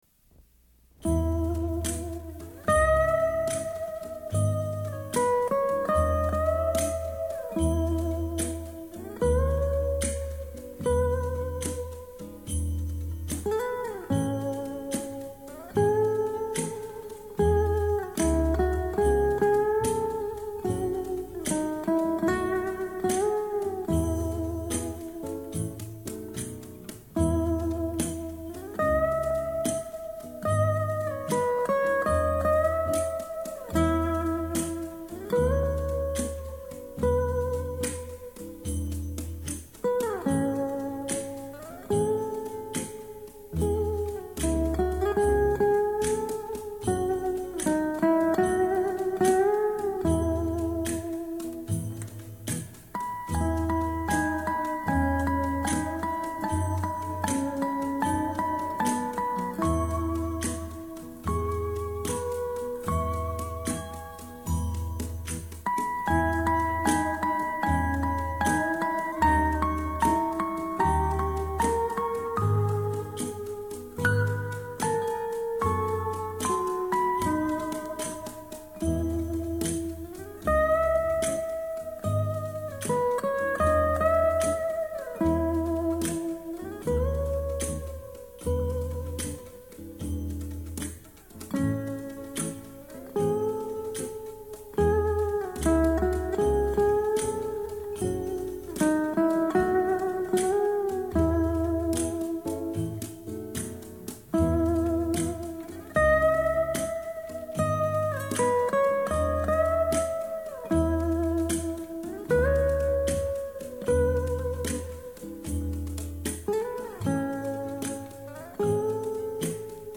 口琴版本
他演绎的版本舒情浪漫、清脆明亮, 尤其在夜晚听更有种难以言喻的味道。